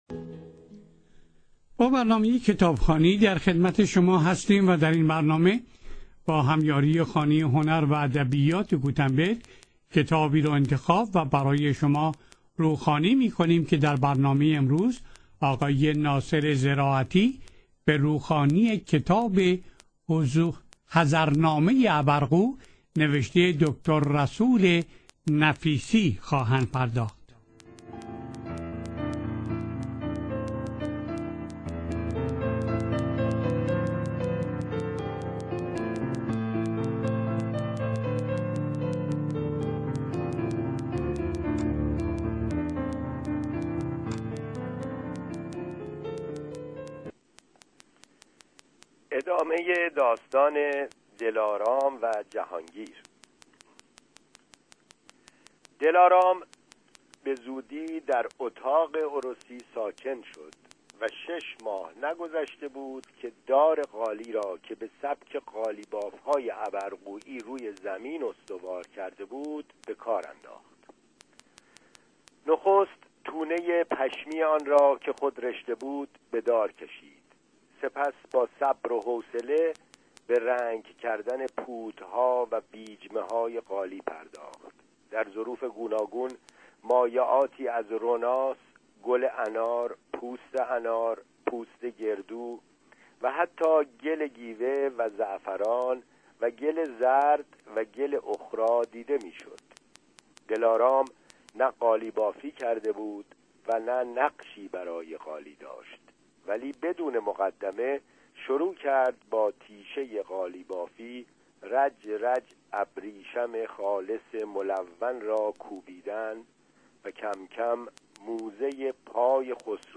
در برنامه کتابخوانی در 16 بخش روخوانی شده